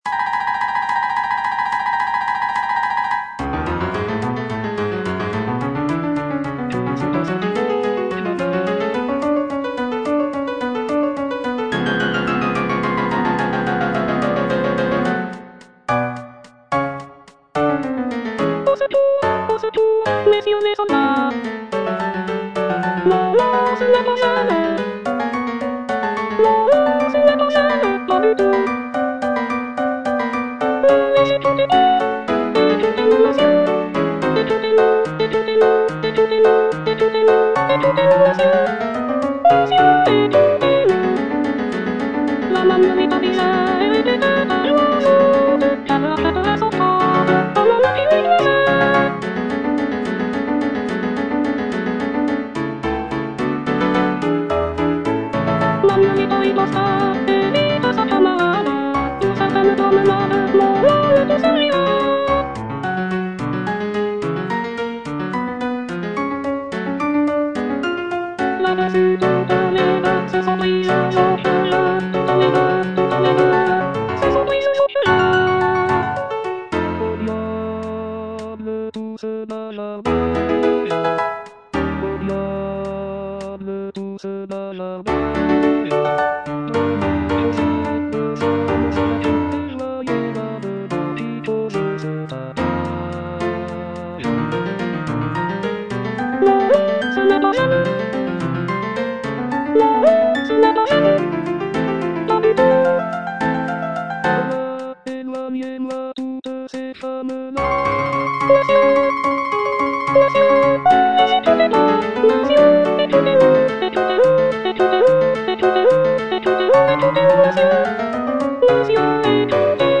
G. BIZET - CHOIRS FROM "CARMEN" Que se passe-t-il donc là-bas? (soprano III) (Voice with metronome) Ads stop: auto-stop